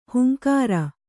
♪ hunkāra